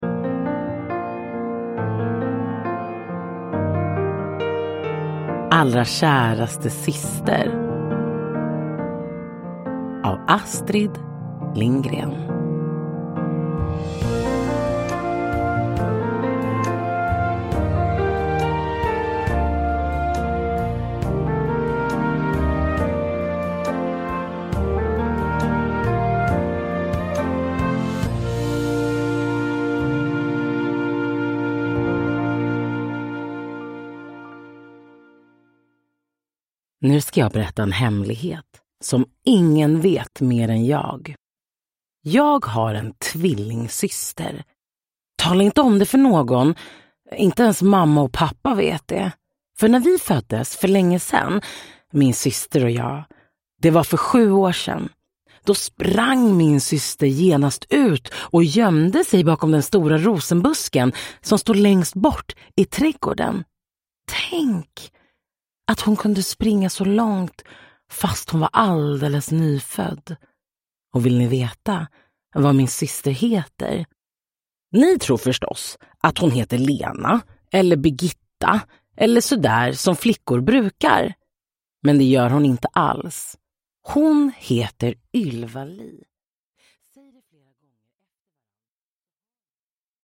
Allrakäraste syster – Ljudbok